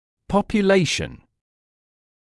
[ˌpɔpjə’leɪʃn] [ˌпопйэ’лэйшн] население